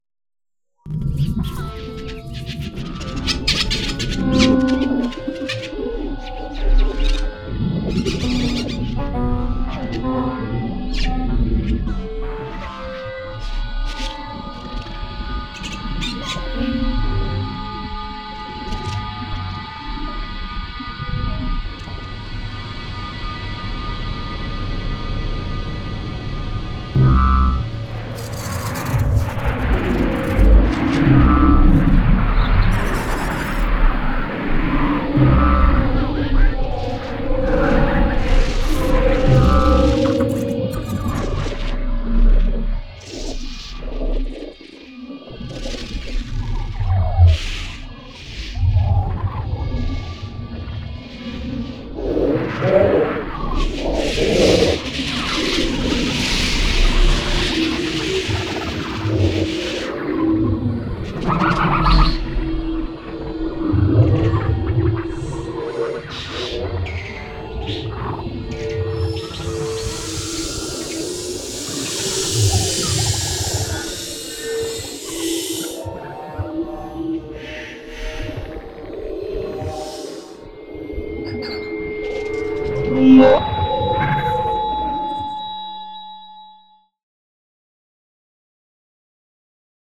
- Flux Obscurs Nous avions également à réaliser 1m30 de contenu, mais cette fois-ci au format Dolby Atmos en utilisant aussi un maximum de techniques d'écriture et de modèles énergétiques différents. Ce programme est construit en 4 parties, tout en gardant un axe principal grâce au flux.
binaurale a été réalisée afin de pouvoir l'écouter au casque.
flux_obscurs_binaural.wav